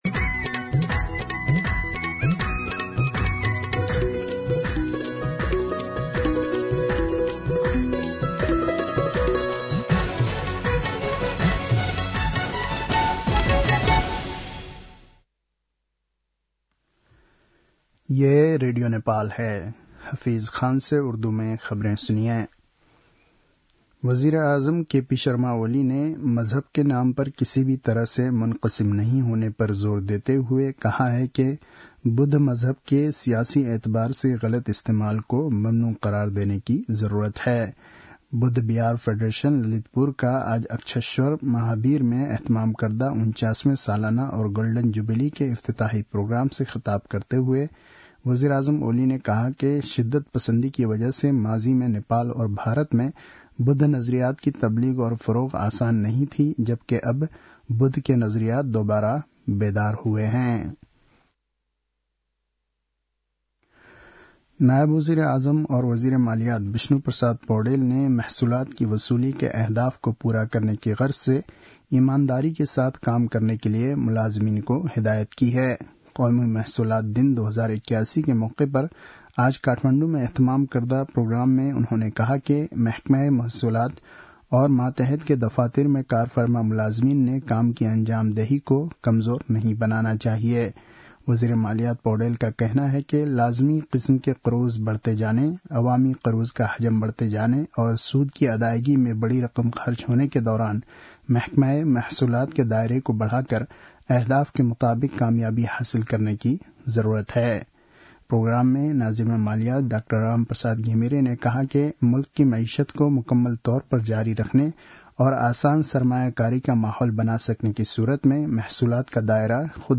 उर्दु भाषामा समाचार : २ मंसिर , २०८१
Urdu-News-8-1.mp3